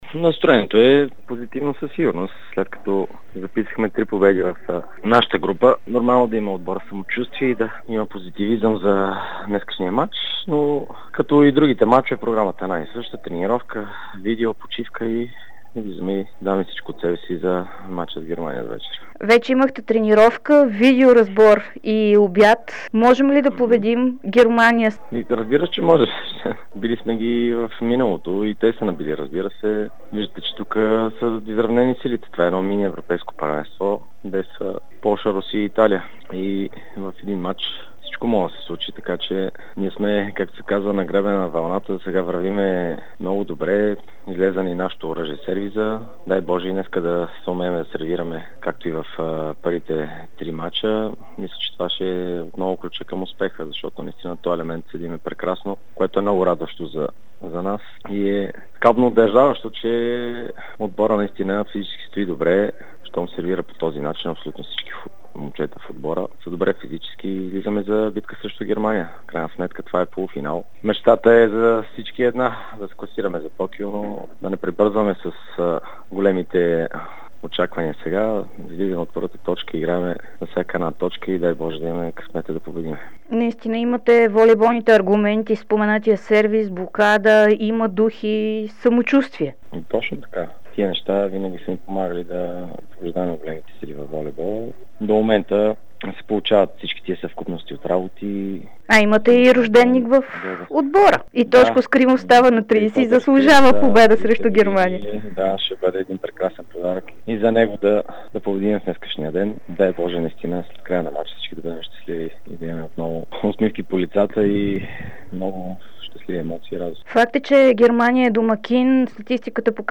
Чуйте какво каза Салпаров в аудиото
Либерото на националния отбор по волейбол Теодор Салпаров заяви пред dsport часове преди полуфинала на европейската олимпийска квалификация, че всички в тима са готови за битка срещу Германия тази вечер.